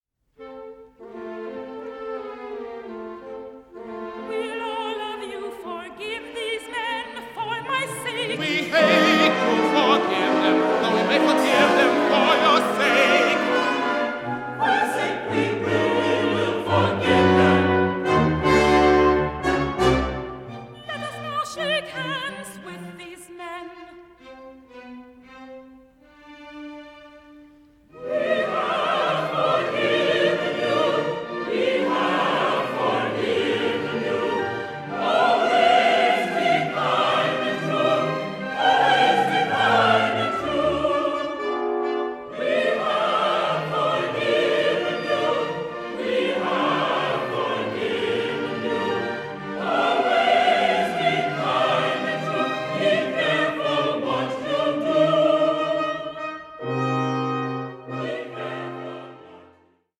Opera in Three Acts
a buoyant blend of ragtime, vaudeville and grand opera